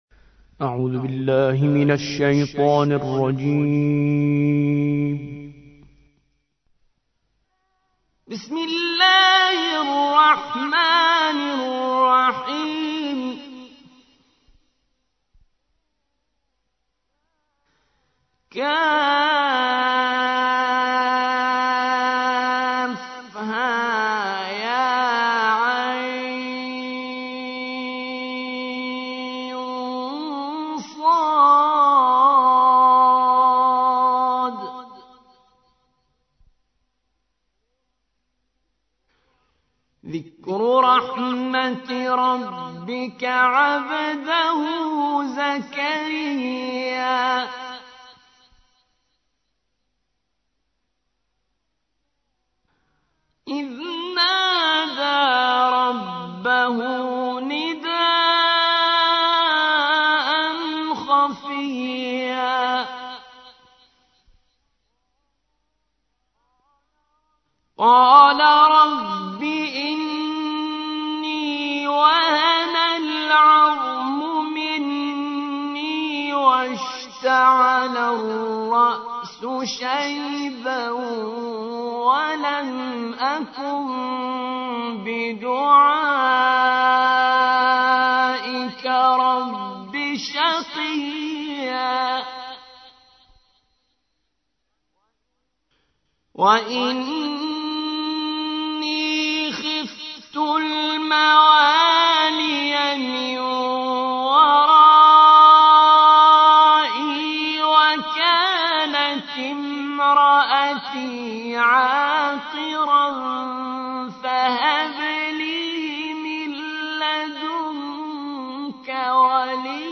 19. سورة مريم / القارئ